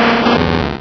pokeemerald / sound / direct_sound_samples / cries / slugma.aif